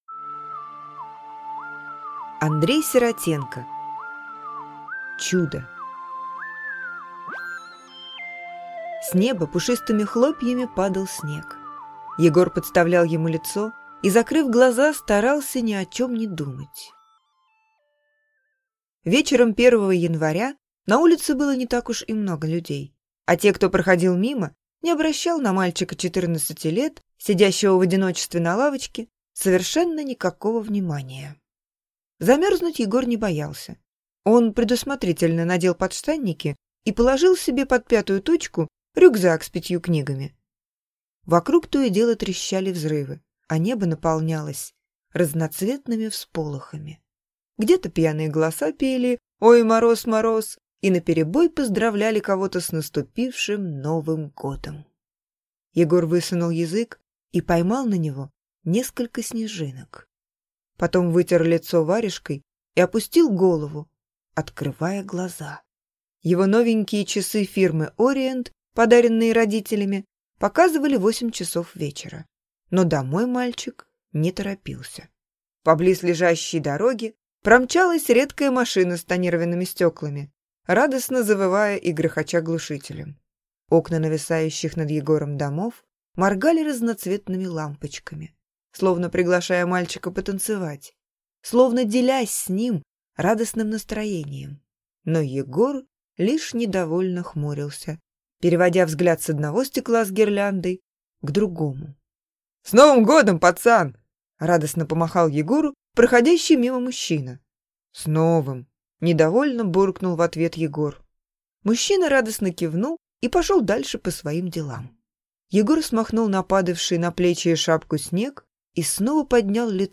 Аудиокнига Чудо | Библиотека аудиокниг